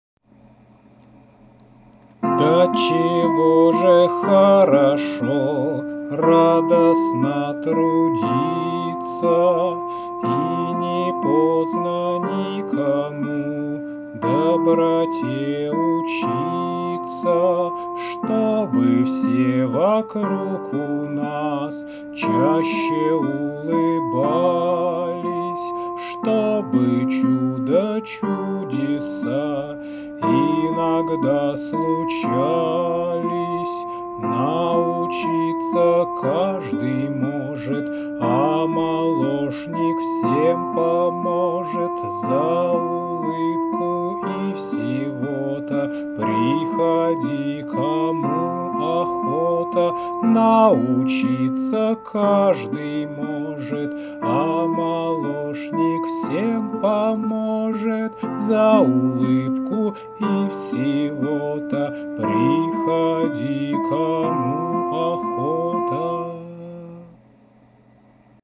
• Жанр: Детская